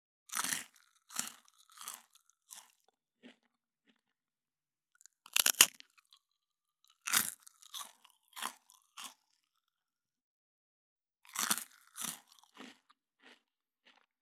12.スナック菓子・咀嚼音【無料効果音】
ASMR/ステレオ環境音各種配布中！！
ASMR